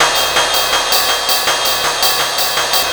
Ride 09.wav